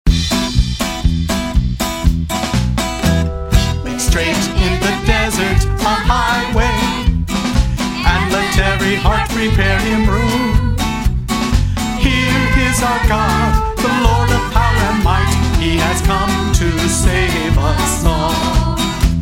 Praise Song for Children